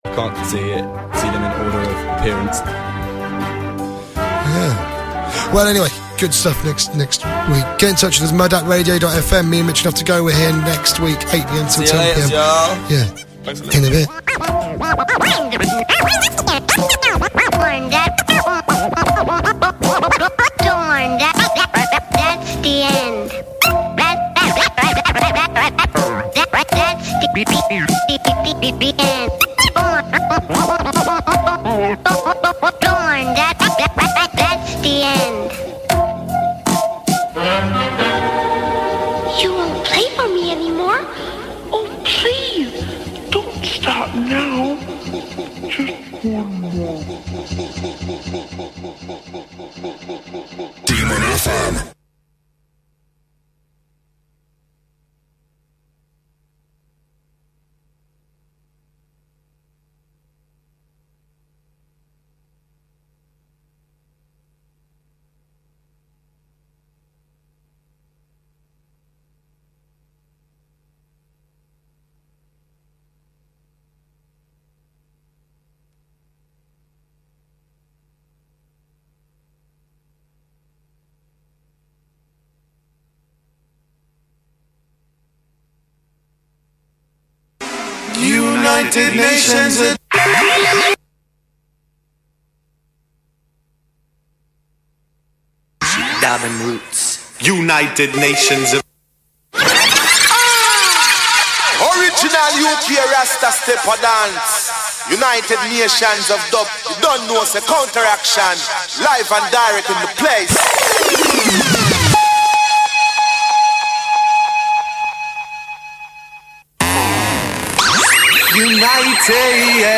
Radio Dubcast